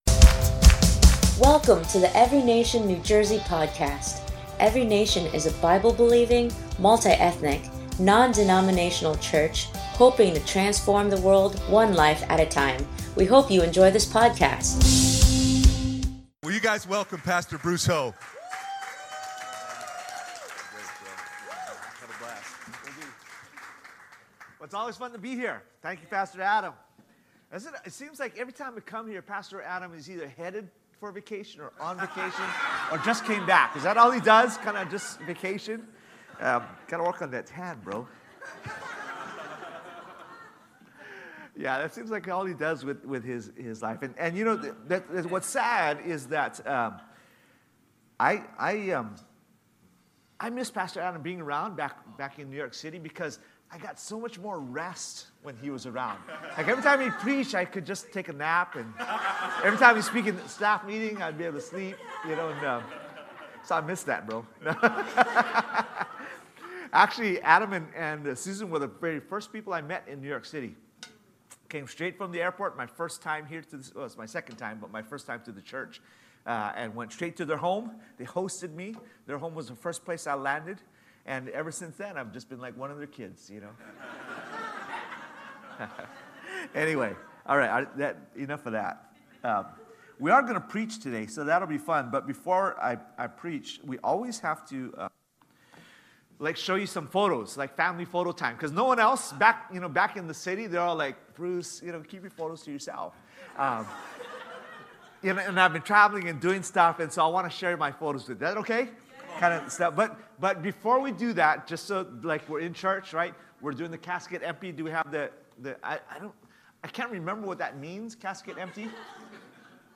Every Nation Church, New Jersey was live.mp3